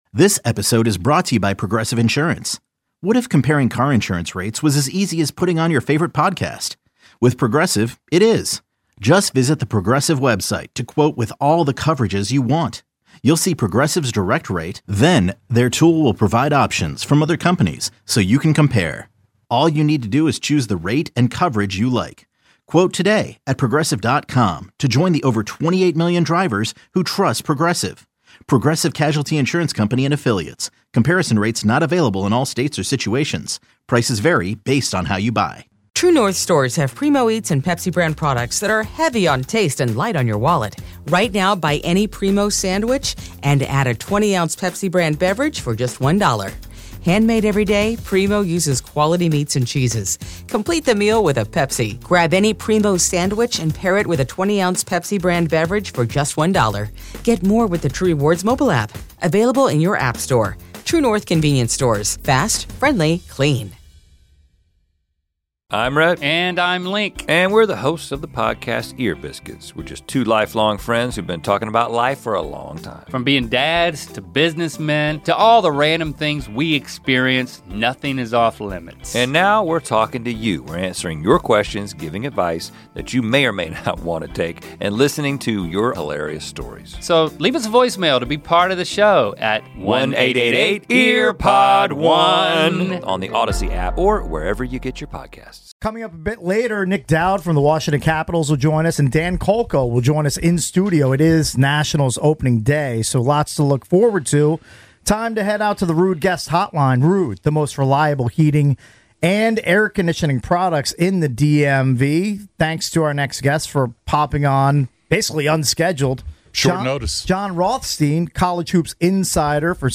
For more than 25 years, The Junks have owned Washington D.C. sports radio, covering Commanders, Nationals, Capitals, Wizards, Hokies, Terrapins, and Hoyas news.